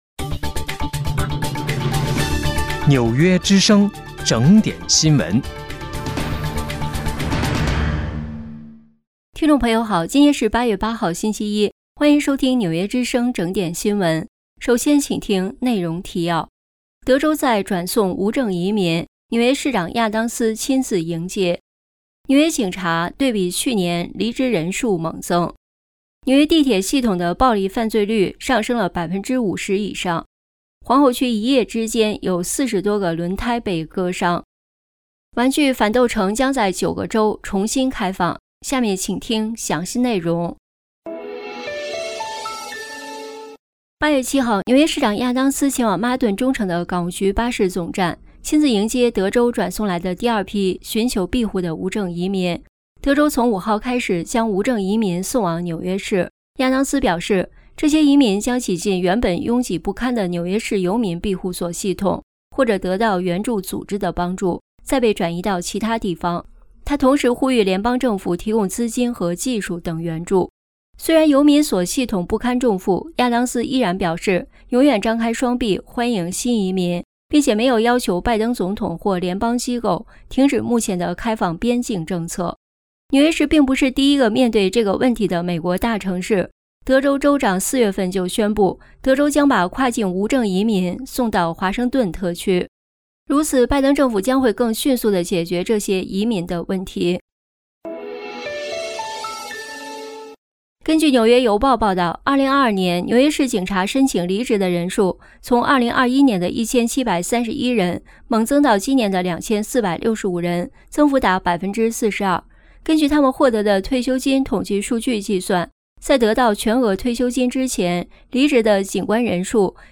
8月8日（星期一）纽约整点新闻